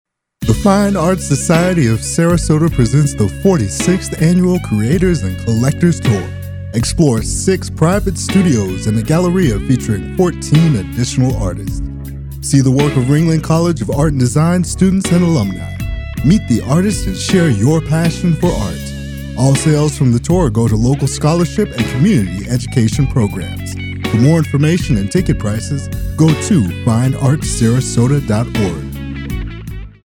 SR Voice Over Commercial Actor + Voice Over Jobs
I sound believable, natural and what was once described as "listenable" - my main focus is on eLearning narration. Hard sell, soft sell, natural, easy to listen to....